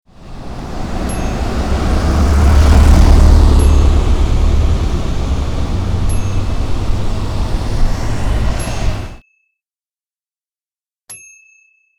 Truck Passes By Sound Effect
Truck passes by on street. Very clean recording with no unwanted noise.
TruckPassesBy.mp3